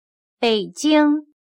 北京/běijīng/Beijing